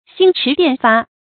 星馳電發 注音： ㄒㄧㄥ ㄔㄧˊ ㄉㄧㄢˋ ㄈㄚˋ 讀音讀法： 意思解釋： 謂迅速如流星閃電。